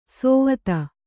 ♪ sōvata